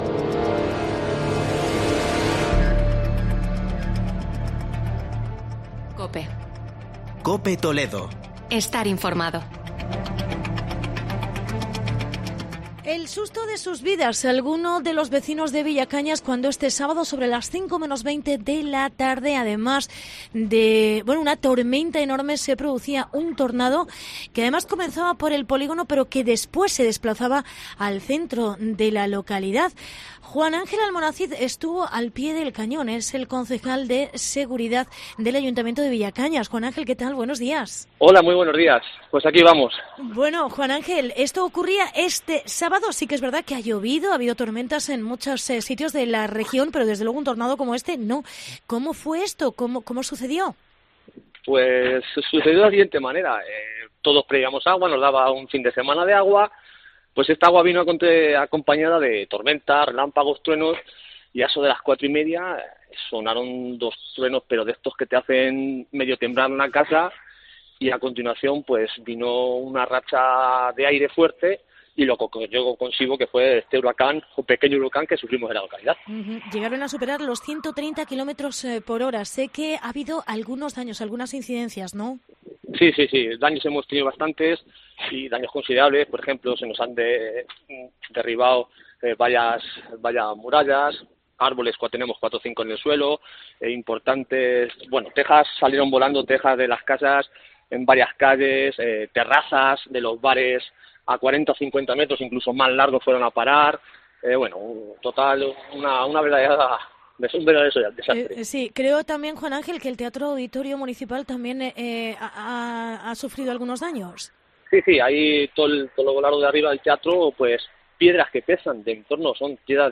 Testimonios de vecinos de Villacañas y entrevista con el concejal Juan Ángel Almonacid